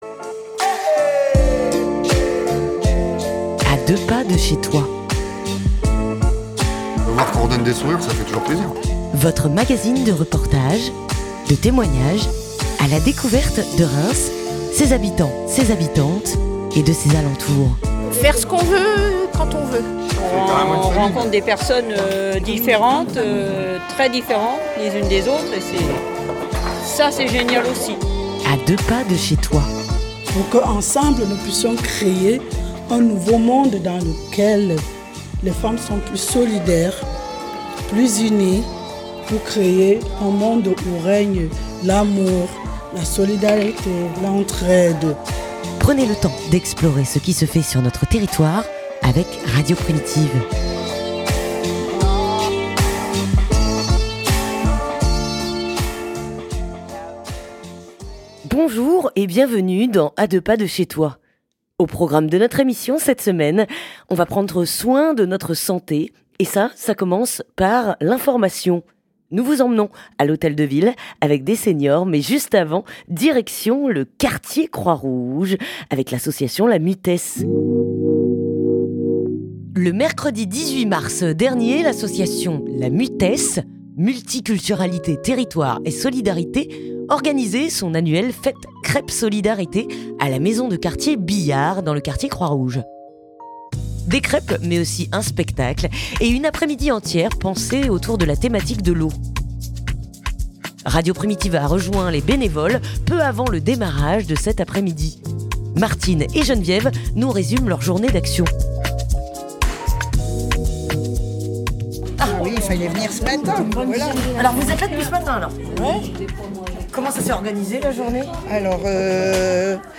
Radio Primitive a rejoint les bénévoles peu avant de démarrage de cette après-midi.